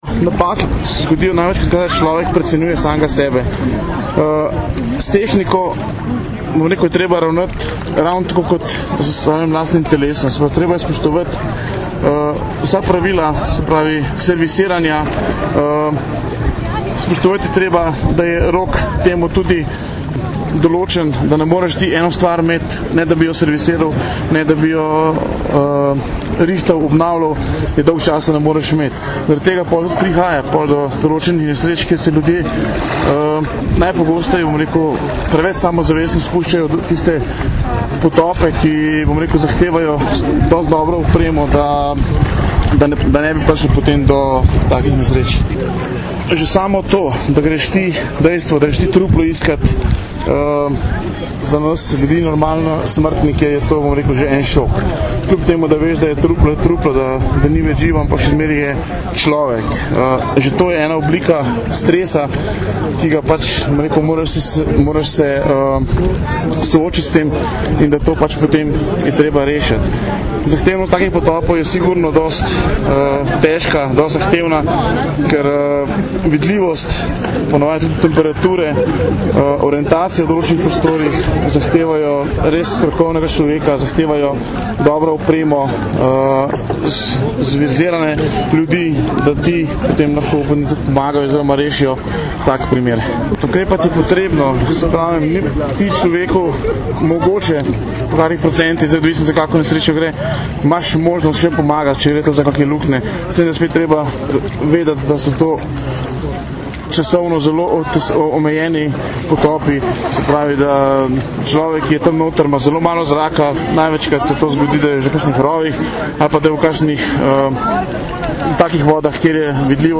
Ob tej priložnosti smo danes, 22. julija 2008, v regatnem centru Zaka na Bledu pokazali tudi delopolicistov potapljačeviz Specialne enote.